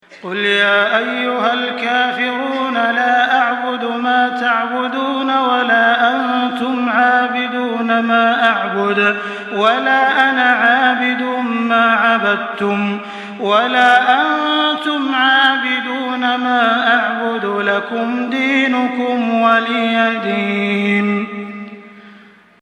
تحميل سورة الكافرون بصوت تراويح الحرم المكي 1424
مرتل